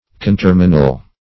Conterminal \Con*ter"mi*nal\, a.
conterminal.mp3